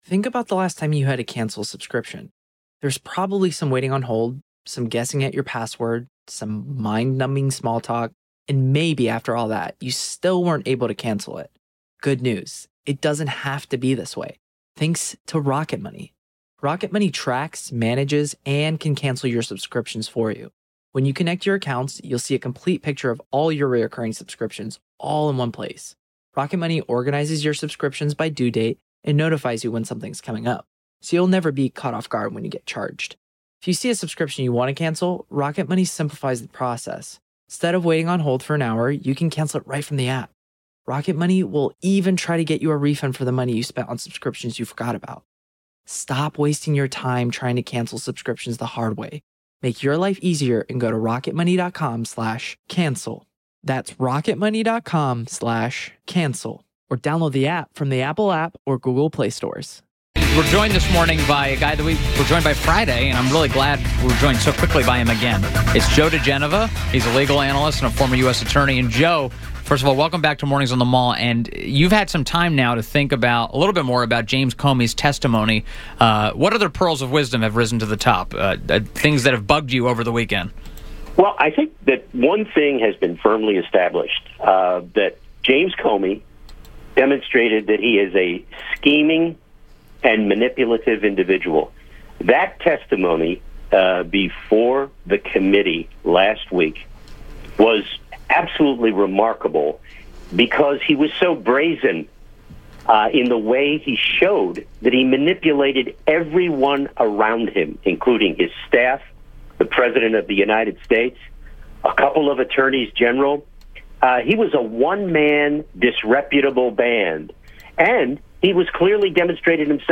WMAL Interview - JOE DIGENOVA 06.12.17